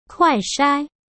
「Kuài shāi」「クアイ サイ」